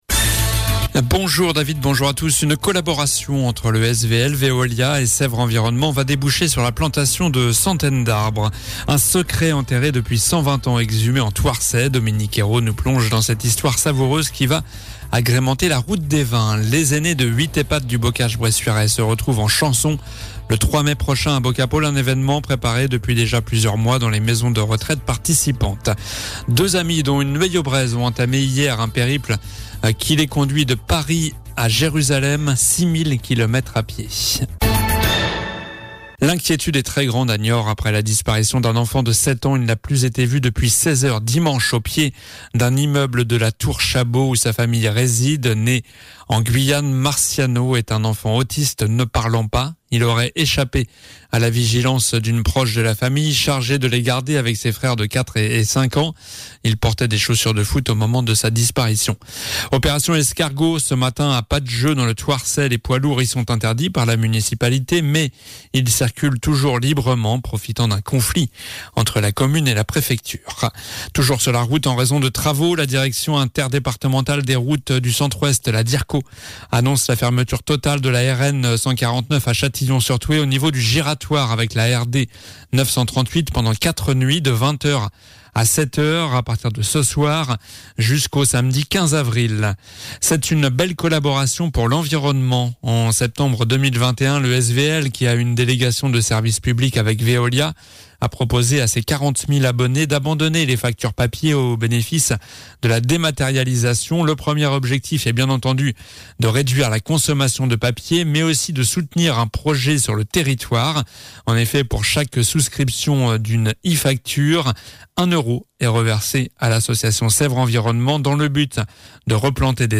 Journal du mardi 11 avril (midi)